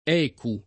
$ku] n. pr. m. — sigla dell’ingl. European Currency Unit «unità monetaria europea» (1979) — anche intesa come un travestim. del s. m. écu [fr. ekü^] e in quanto tale tradotta in scudo o euroscudo